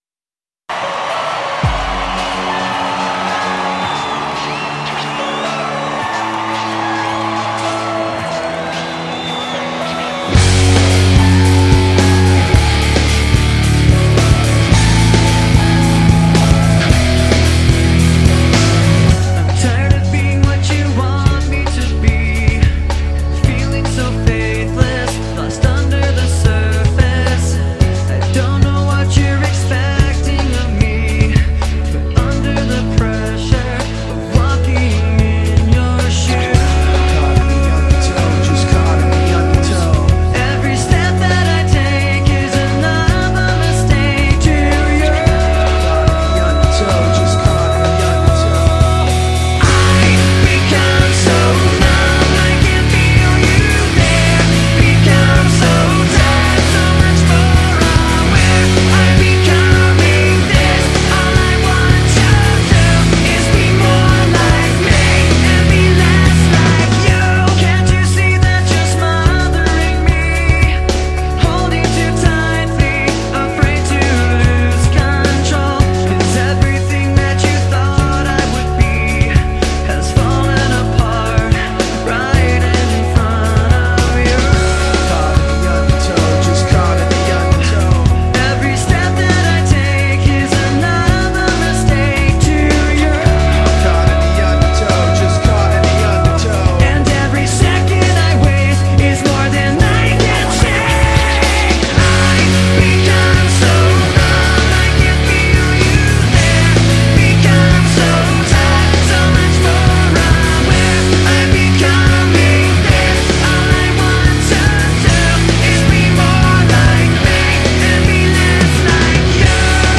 Nu-Metal